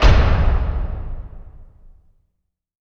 LC IMP SLAM 4B.WAV